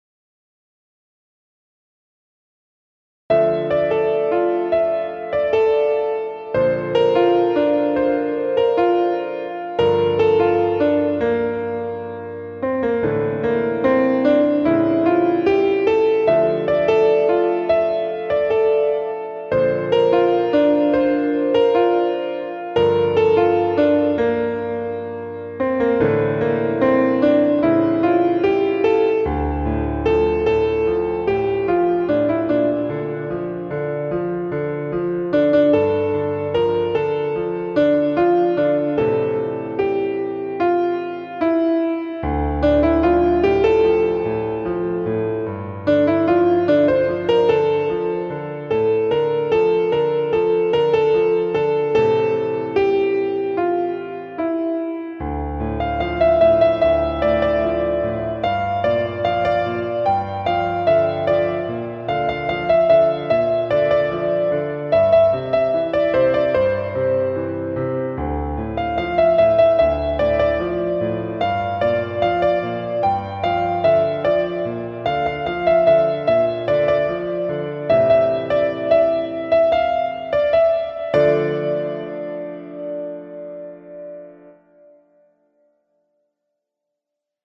• اجرای دقیق با تمپوی استاندارد و بیان نرم
• کمک به درک حس اندوه‌ناک و عاشقانه قطعه